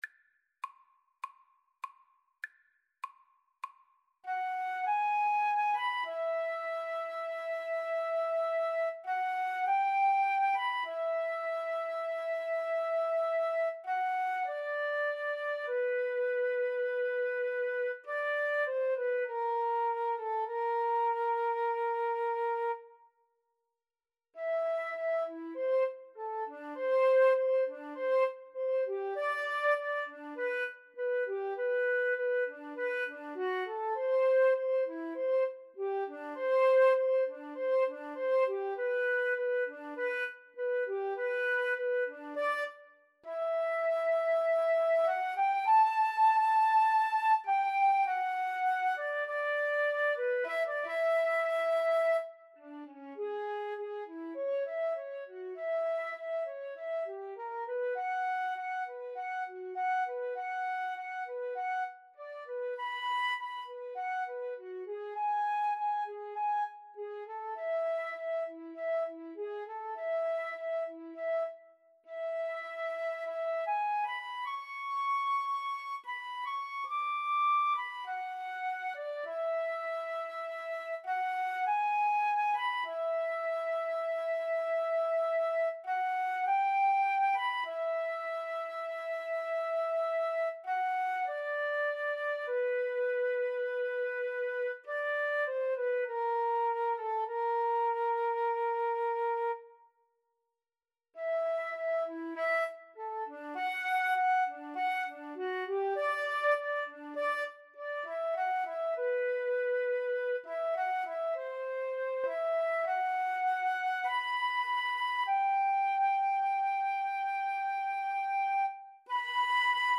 A sultry piece in the rhythm known as 'Beguine'.
4/4 (View more 4/4 Music)